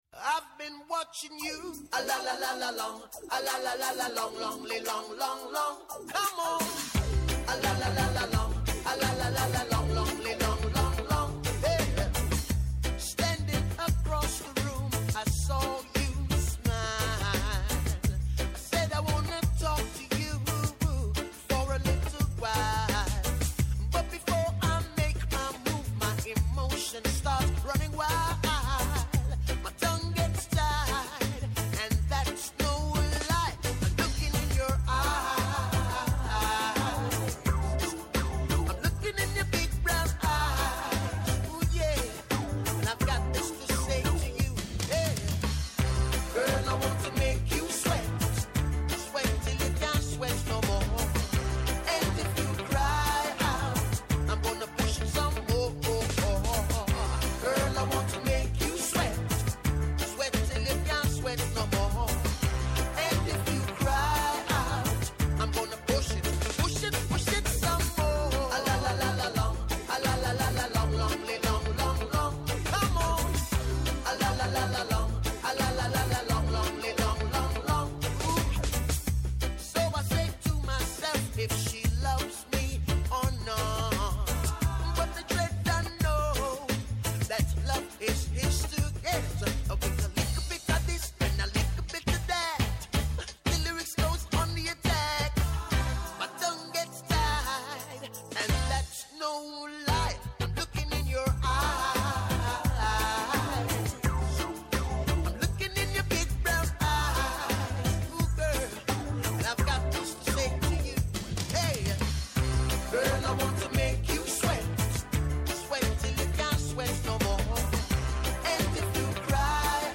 -Ο Θανάσης Βυρίνης, Αντιδήμαρχος Τουρισμού Δήμου Ρόδου
-Ο Σάββας Χριστοδούλου, Αντιδήμαρχος Νότιας Ρόδου και Καμείρου
Με ζωντανά ρεπορτάζ από όλη την Ελλάδα, με συνεντεύξεις με τους πρωταγωνιστές της επικαιρότητας, με ειδήσεις από το παρασκήνιο, πιάνουν τιμόνι στην πρώτη γραμμή της επικαιρότητας.